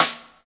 Index of /90_sSampleCDs/Roland L-CD701/SNR_Rim & Stick/SNR_Stik Modules
SNR THIN S0G.wav